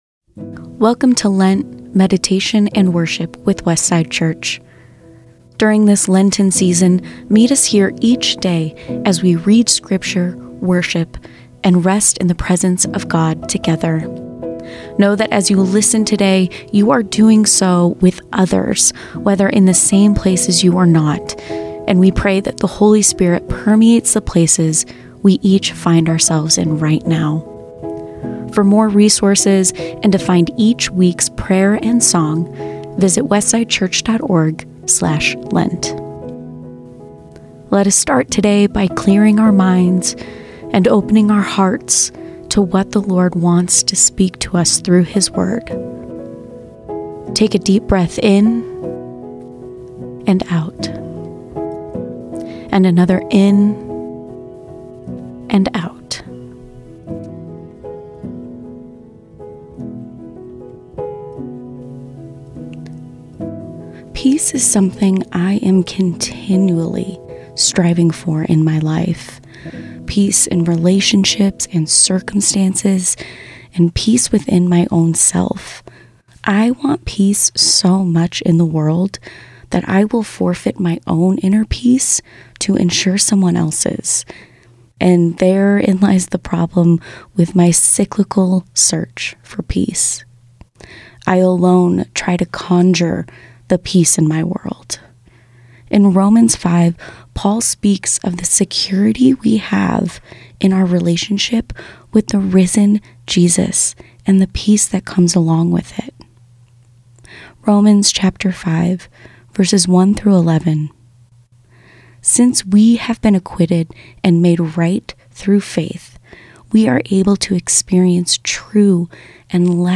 A reading from Romans 5:1-11 A prayer for your week: Enduring Presence, goal and guide, you go before and await our coming.